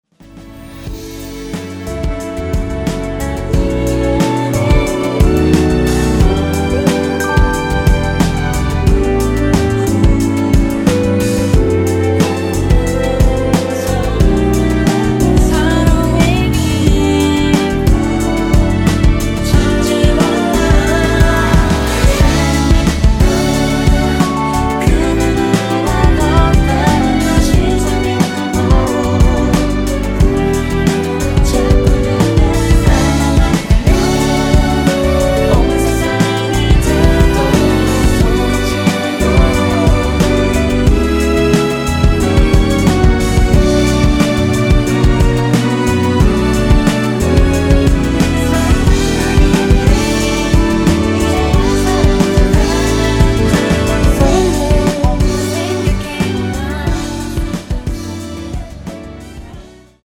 원키에서(-1)내린 코러스 포함된 MR입니다.